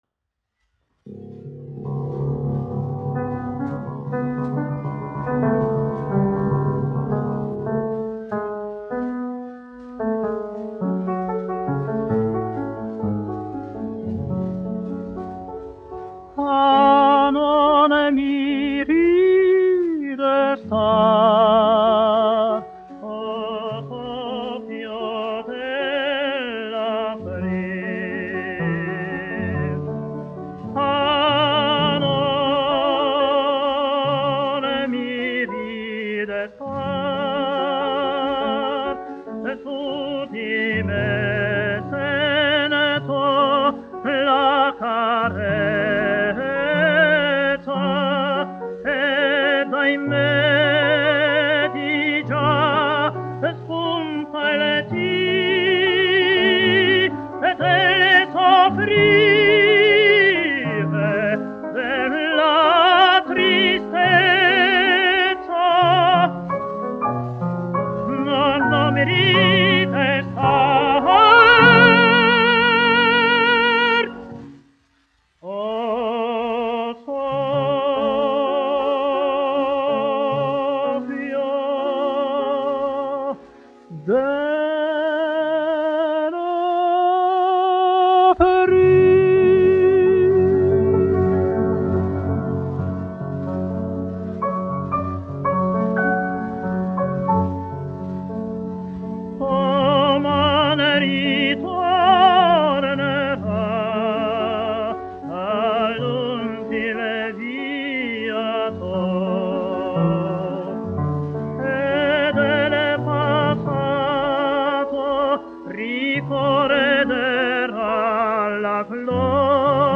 1 skpl. : analogs, 78 apgr/min, mono ; 25 cm
Operas--Fragmenti, aranžēti
Skaņuplate